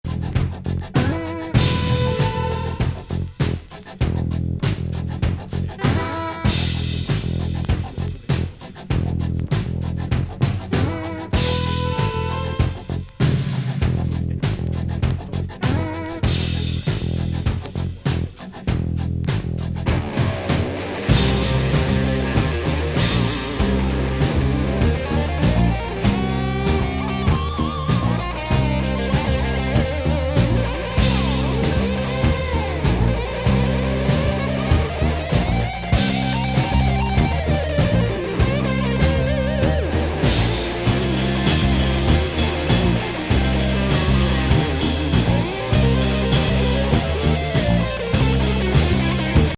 テクノ歌謡 / NW